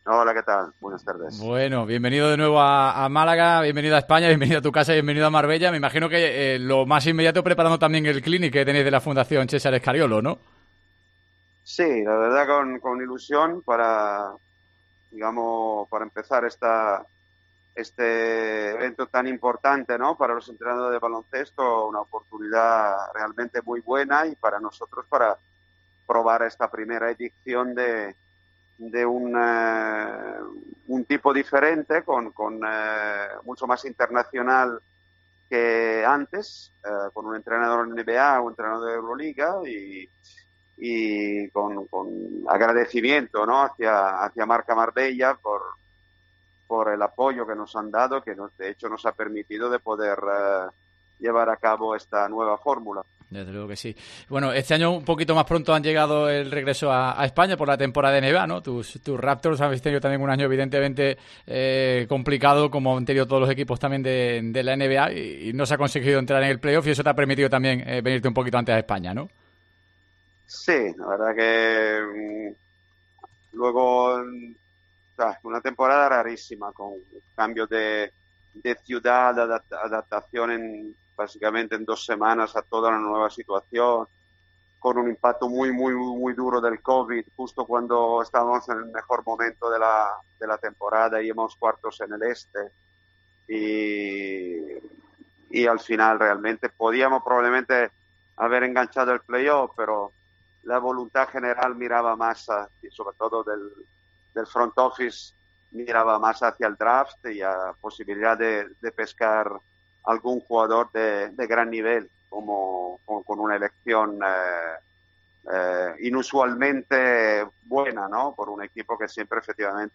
AUDIO: El seleccionador analiza la actualidad del baloncesto en Deportes COPE Málaga